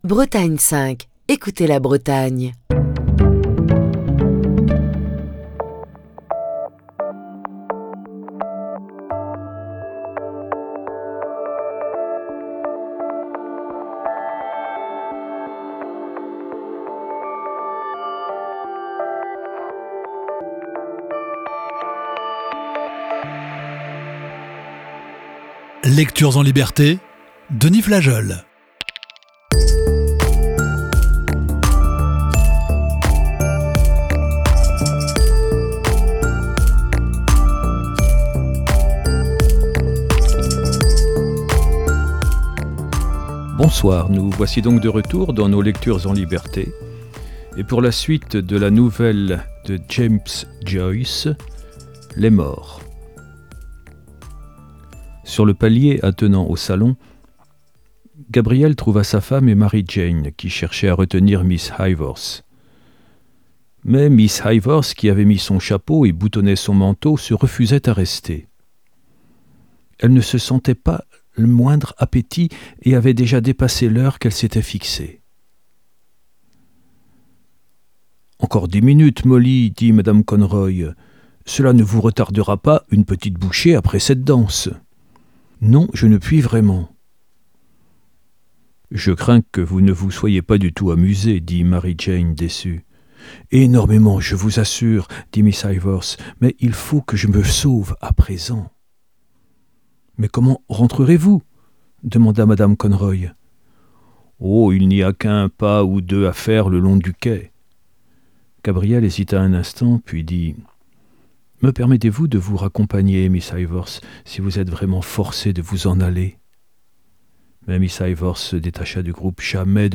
lit des extraits du roman de James Joyce, "Les morts".